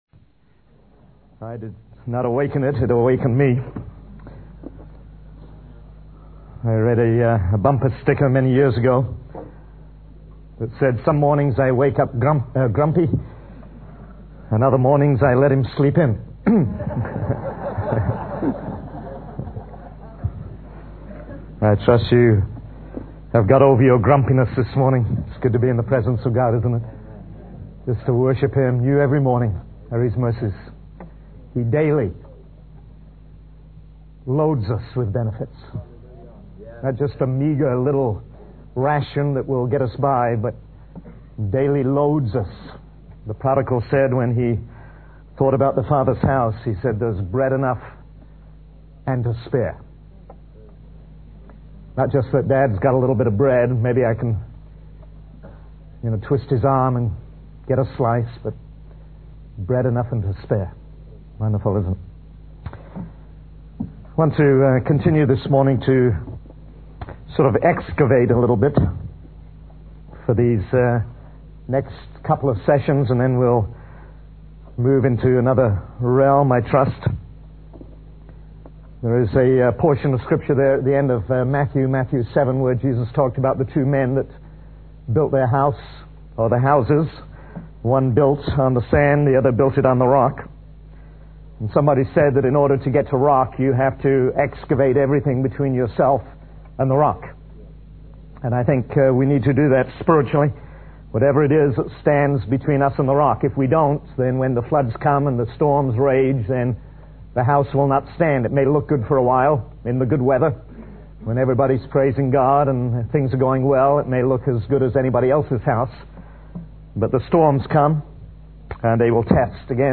In this sermon, the preacher discusses the concept of forgiveness and the importance of extending it to others. He starts by sharing a story about a man who had an impossible debt and was about to be sold along with his family to repay it.